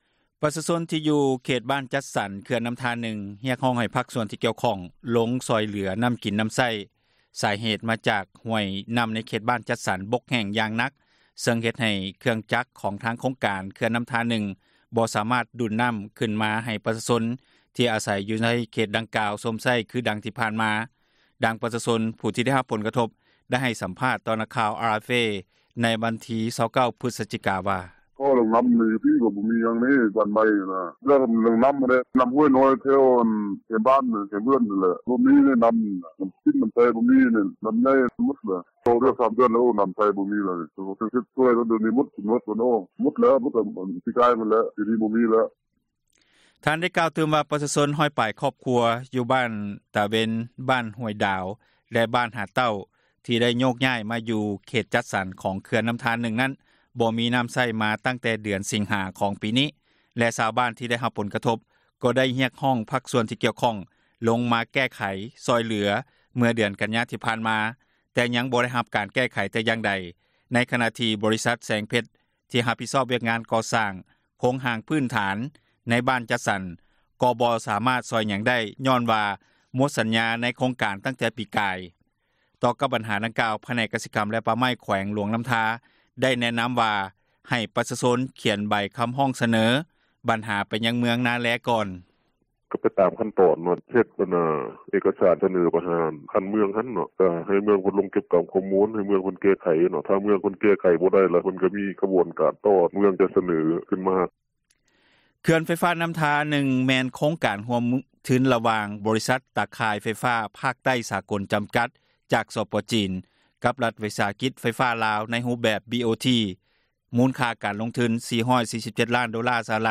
ດັ່ງປະຊາຊົນຜູ້ໄດ້ຮັບ ຜົລກະທົບ ໄດ້ໃຫ້ສຳພາດຕໍ່ ນັກຂ່າວ RFA ໃນວັນທີ 29 ພຶສຈິກາ ນີ້ວ່າ: